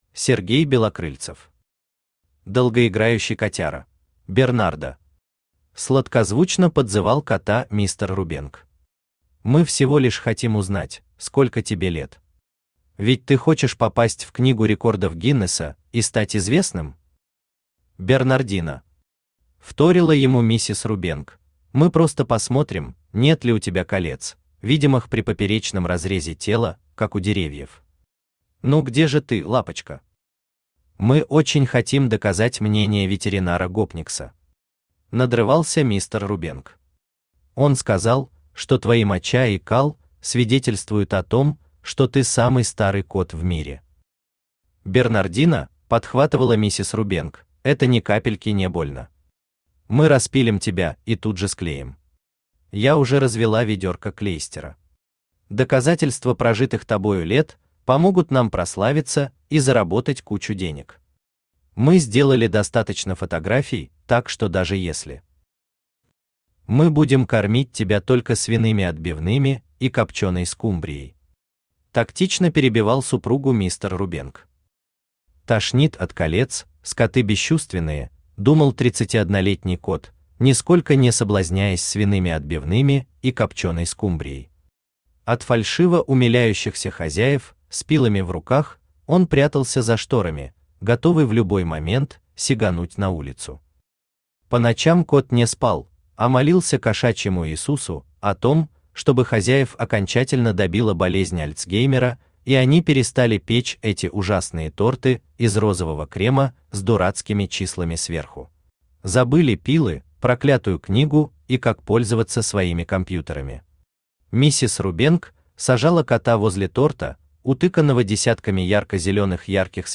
Aудиокнига Долгоиграющий котяра Автор Сергей Валерьевич Белокрыльцев Читает аудиокнигу Авточтец ЛитРес.